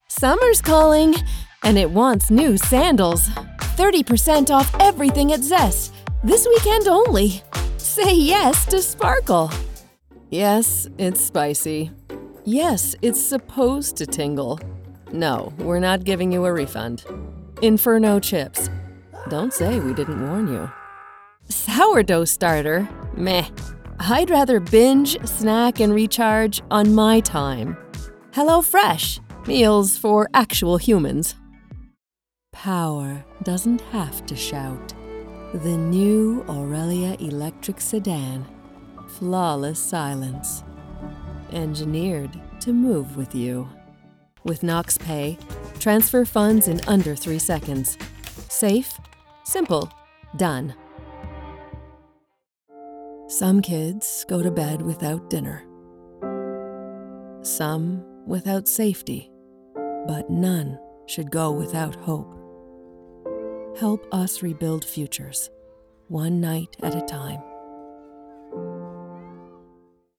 Warm, flexible, seasoned, and authentic.
Commercial Sample